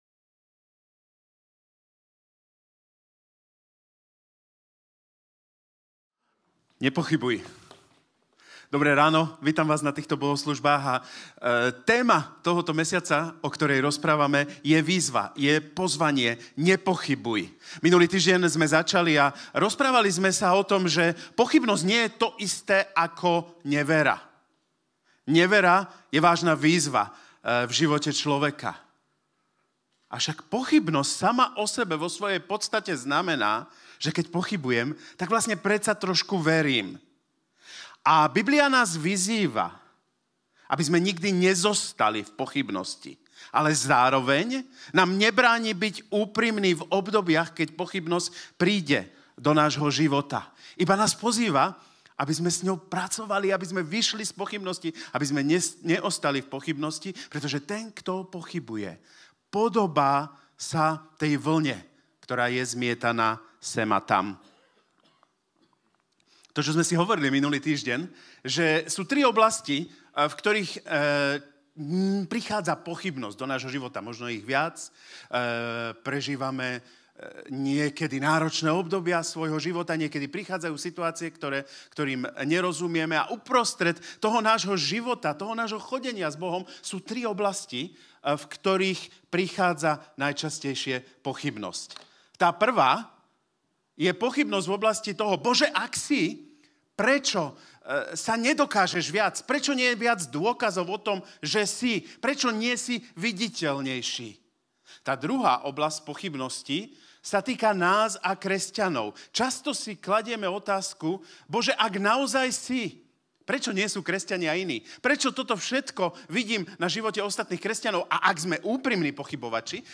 V sérii kázní NEPOCHYBUJ chcem odvážne odpovedať na skutočne ťažké otázky. V dnešnej kázni smelo vyťahujeme otázky, ako "ako dokážeme, že Boh je?".